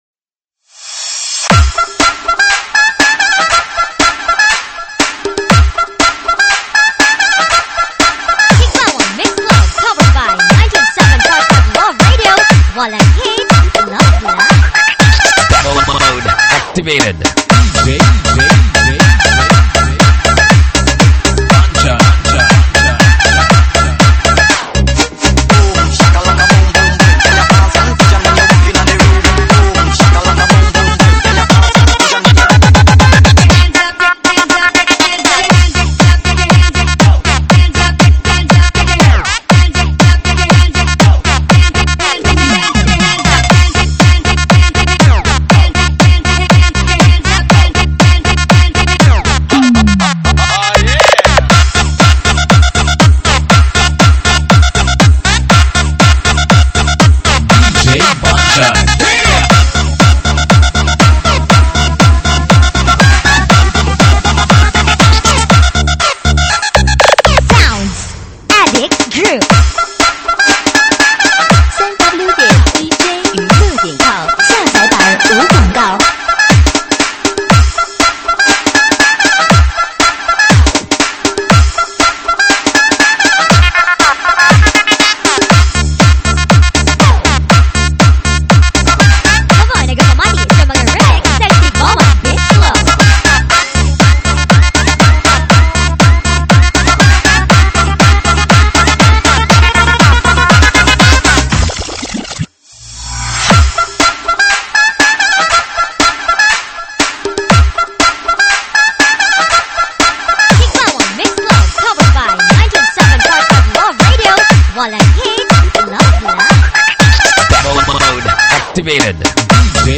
舞曲类别：慢摇舞曲